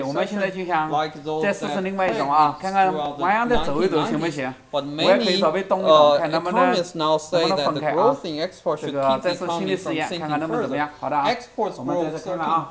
Wre also include a couple of real recordings examples, each of which consists of a two-speaker mixture recorded in two channels. In the second example, one of the speakers was in motion.
Real Recording 2 (regular office, better microphones about $100 apiece, moderately echoic, one speaker was walking back and forth).
two-walking10cm-v1-16k.wav